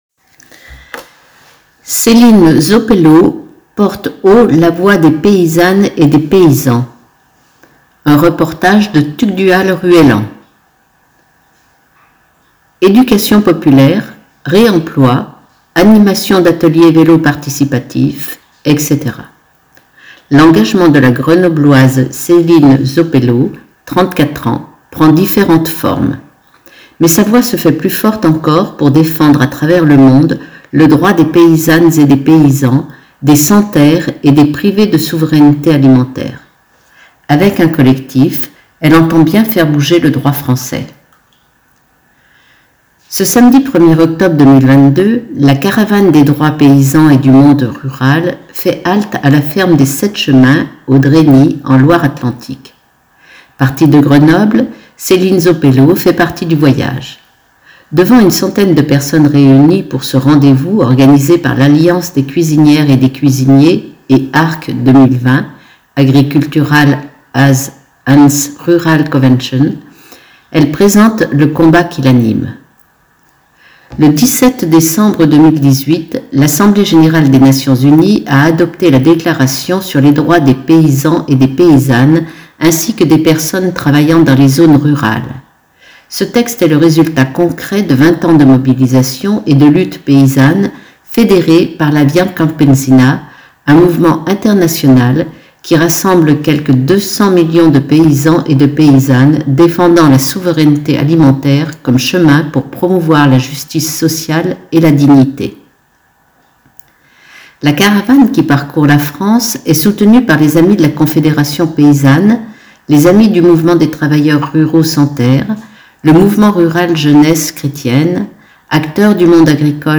Ce samedi 1er octobre 2022, l a Caravane des droits paysans et du monde rural fait halte à la ferme des Sept chemins au Dresny en Loire-Atlantique.